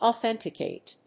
/əθénṭəkèɪt(米国英語), ɔːénṭəkèɪt(英国英語)/
フリガナオセンタケイト